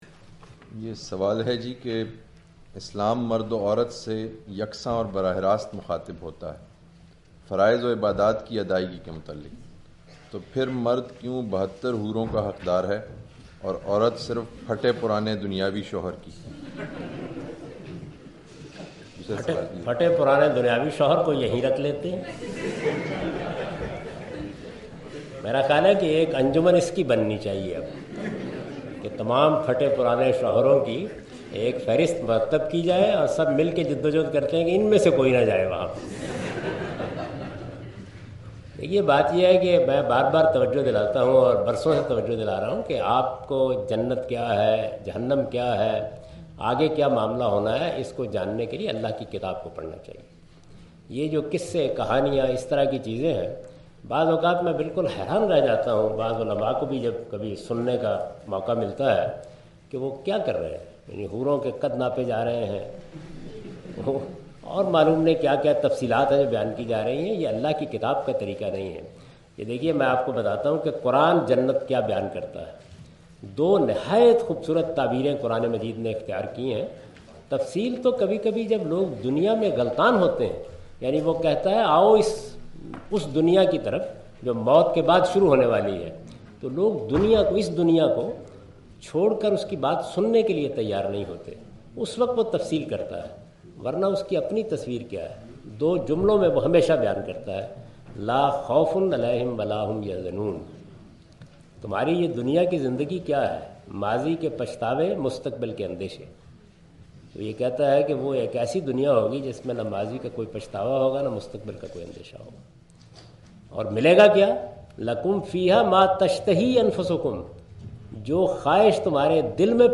Javed Ahmad Ghamidi answers the question,"Reality of Jannah (Paradise)" during his UK visit in Queen Mary on March 13, 2016